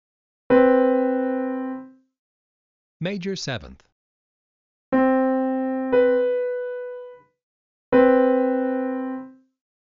11 Major Seventh
Major_Seventh_Example.mp3